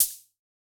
Index of /musicradar/retro-drum-machine-samples/Drums Hits/Tape Path A
RDM_TapeA_MT40-OpHat01.wav